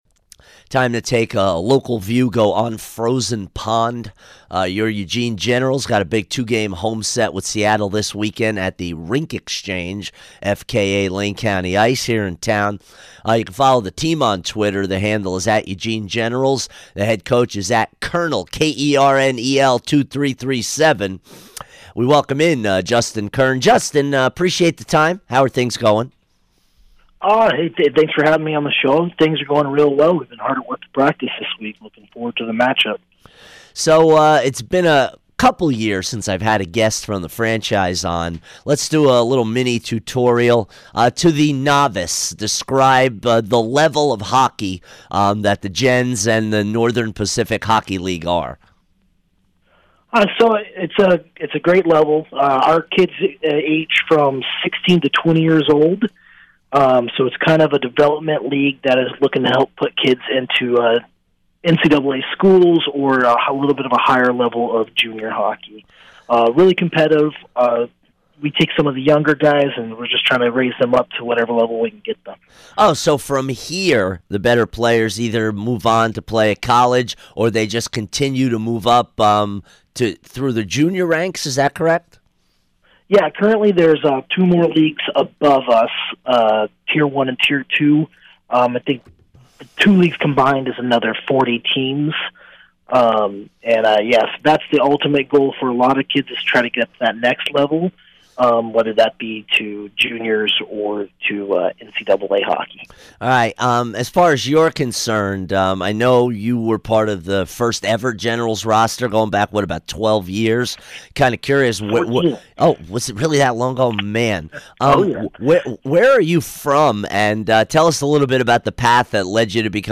Sports Talk